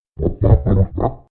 Boss_COG_VO_question.ogg